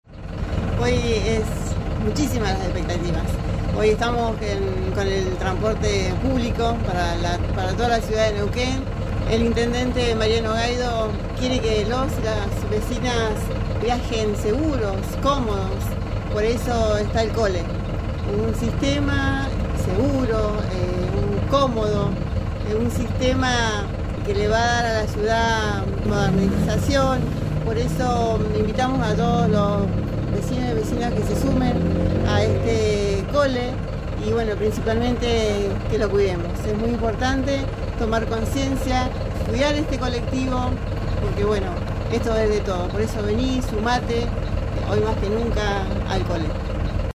Escuchar audio. Claudia Argumero, presidenta del Concejo Deliberante.